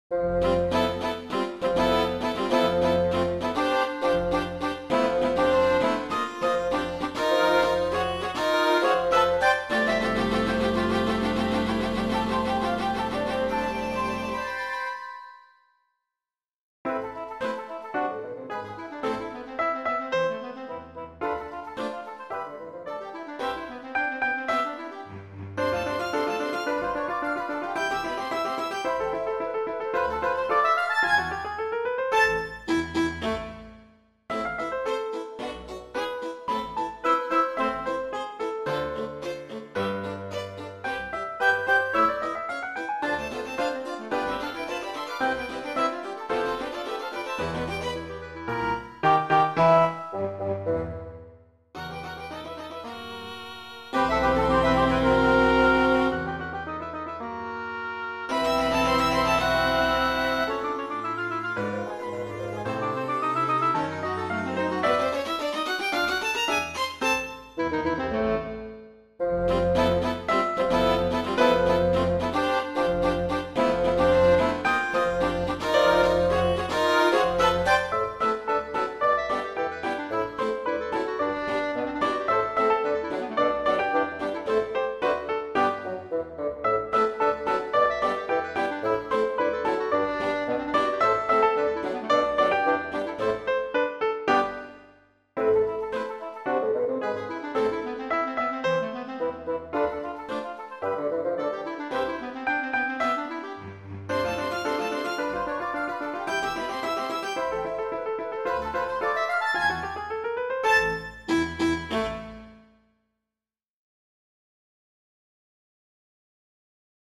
(a-moll)